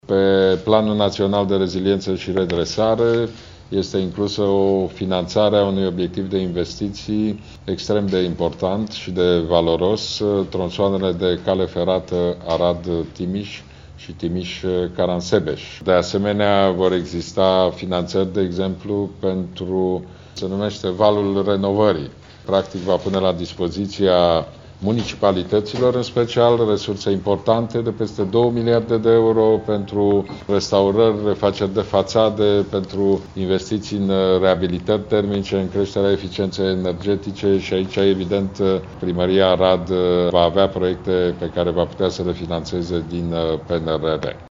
Liderul liberalilor a anunțat la Arad că printre altele, Primăria Arad va putea accesa fonduri pentru reabilitări termice sau pentru restaurări.